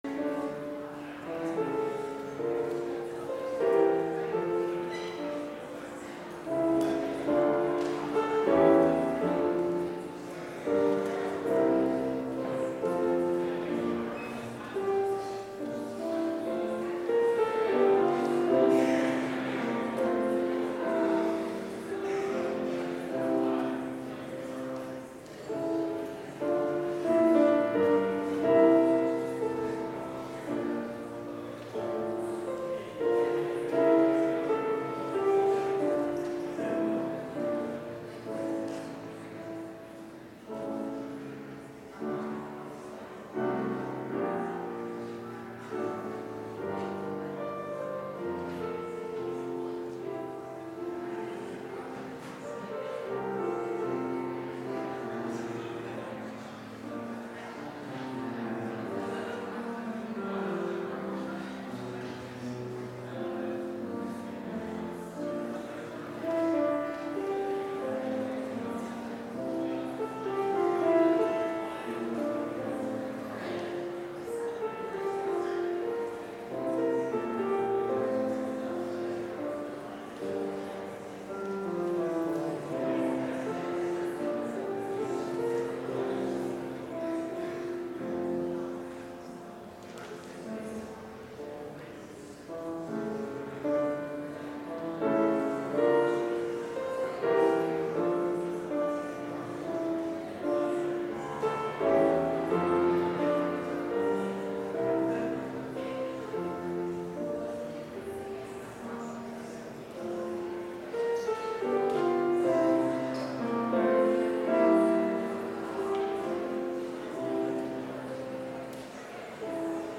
Complete service audio for Chapel - December 10, 2019